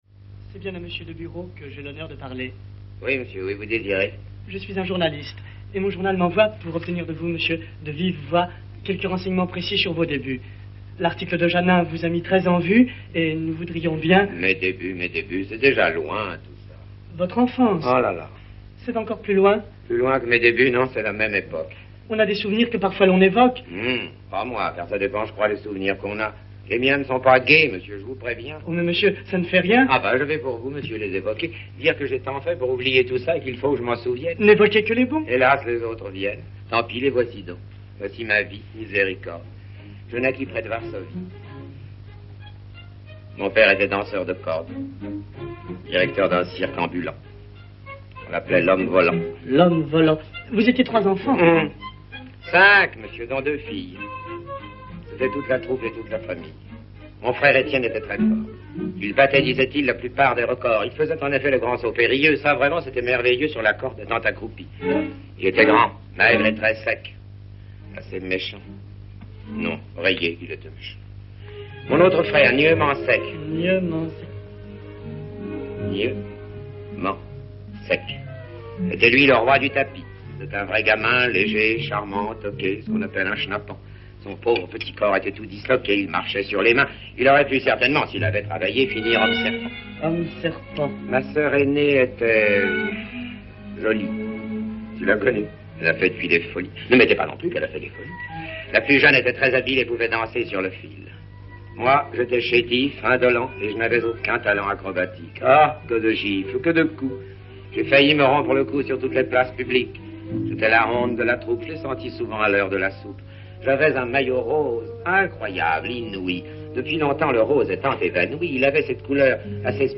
bande son du film, 1951
Guitry - Deburau - film - scene interview.mp3